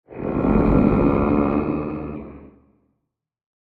Minecraft Version Minecraft Version latest Latest Release | Latest Snapshot latest / assets / minecraft / sounds / mob / warden / agitated_2.ogg Compare With Compare With Latest Release | Latest Snapshot
agitated_2.ogg